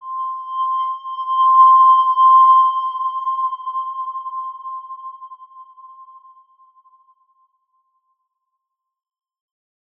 X_Windwistle-C5-ff.wav